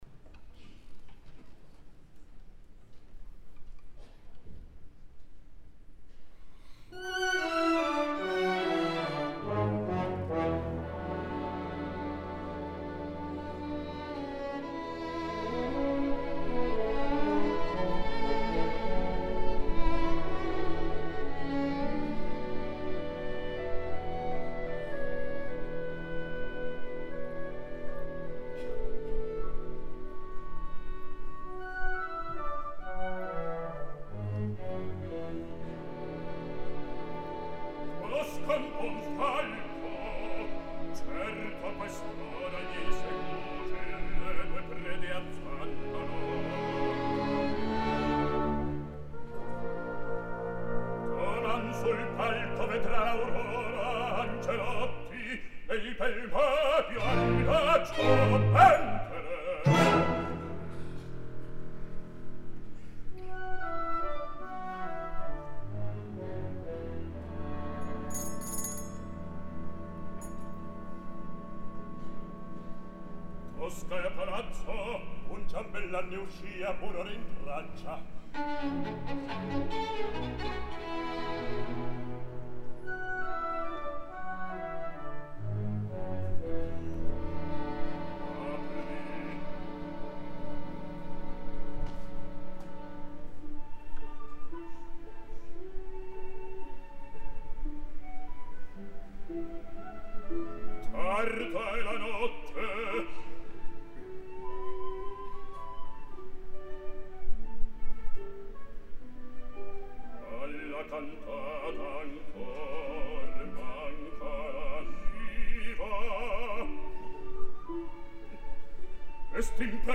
La veu és plena i ampla, d’un color que en a mi em sembla bellíssim i un vibrato, que no s’ha de confondre amb oscil·lació, molt característic i que en a mi no em molesta tant com a molts i que ella utilitza per reforçar la notable intensitat dramàtica, a vegades massa exagerada, per